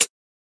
edm-hihat-19.wav